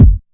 Warm Kick Drum One Shot F# Key 215.wav
Royality free kick drum tuned to the F# note. Loudest frequency: 111Hz
warm-kick-drum-one-shot-f-sharp-key-215-El4.ogg